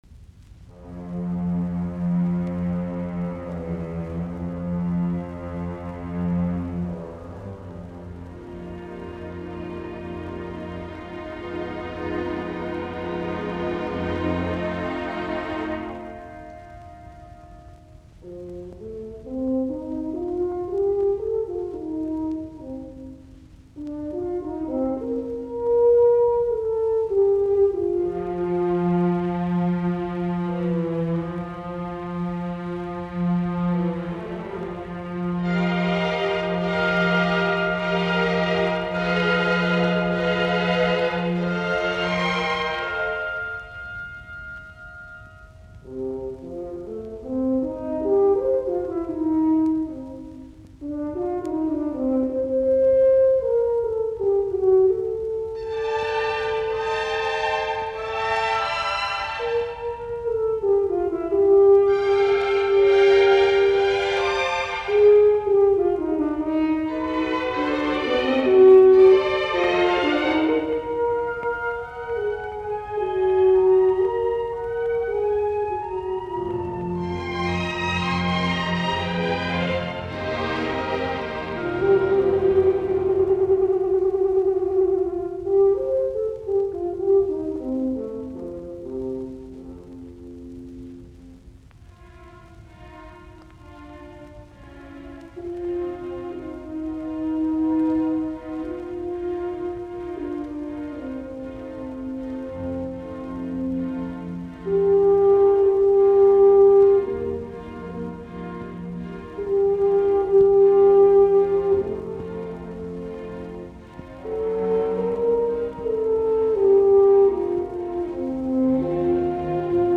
Larghetto for Horn and Orchestra